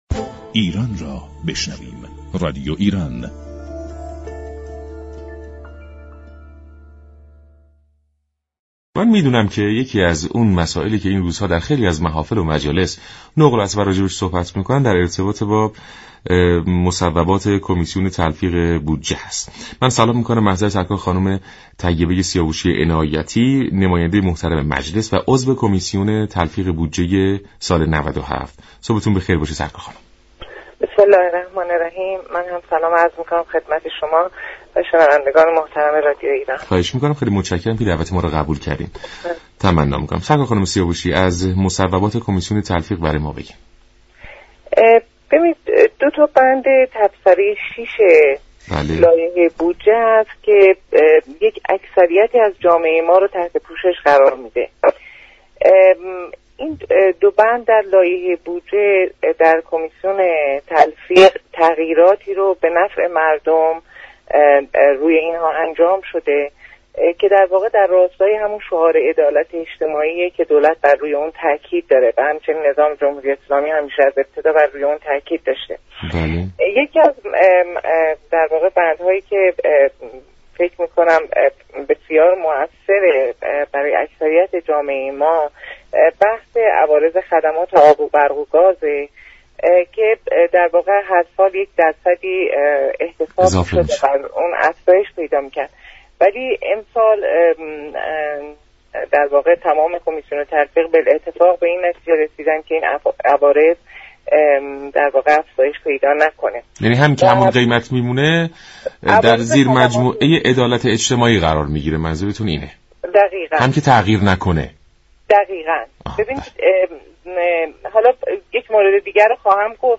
نماینده مجلس و عضو كمیسیون تلفیق بودجه 97 در گفت و گو با برنامه سلام ایران گفت: كارمندانی كه حقوق كمتر از 2 میلیون و 760 هزار دریافت می كنند در سال آینده از حقوق دریافتی شان هیچگونه مالیاتی كسر نخواهد شد.